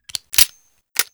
🌲 / midnight_guns mguns mgpak0.pk3dir sound weapon magnum
snd_revolver_reload_REPLACEME.wav